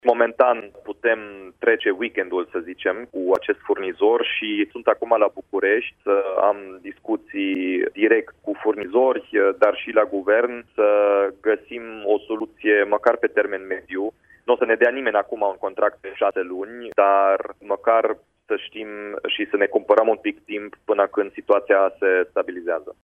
Gospodăriile din Timișoara tot nu au apă caldă și căldură pentru că funcționează doar rețeaua primară a Colterm. Precizarea a fost făcută, la Radio Timișoara, de primarul Dominic Fritz.